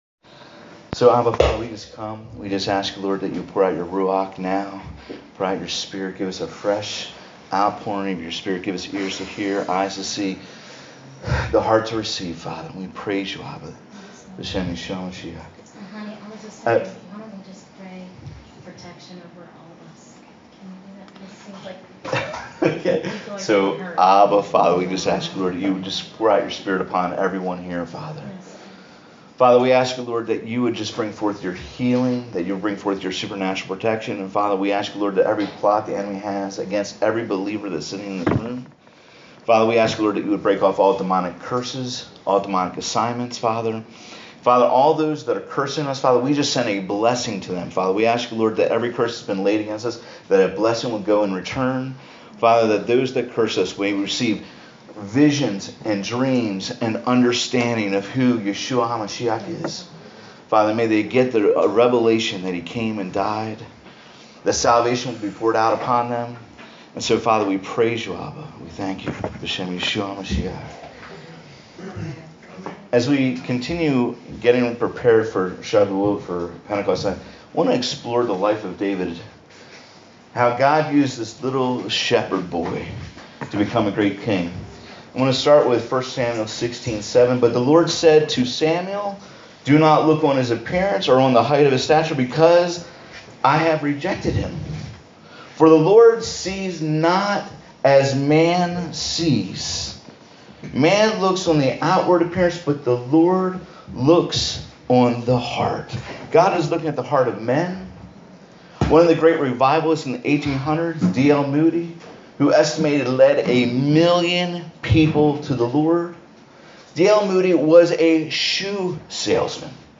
Message — Do You Really Want to be Spirit-Filled?